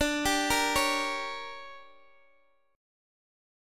DM7sus4#5 Chord